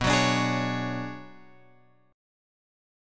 B Minor 6th Add 9th